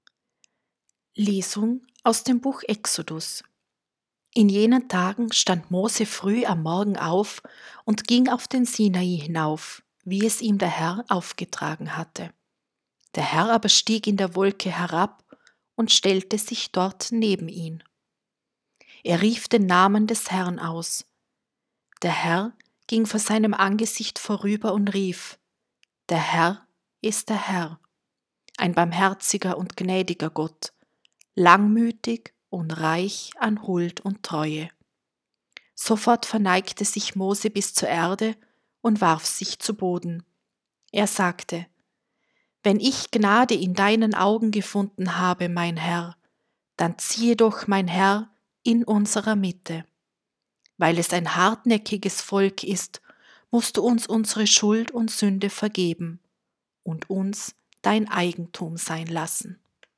Gottes Antlitz in den Geringsten 1. Lesung: Ex 34,4b.5-6.8-9 | 2. Lesung: 2 Kor 13,11-13| Evangelium: Joh 3,16-18
1.-Lesung-7.6.-1.m4a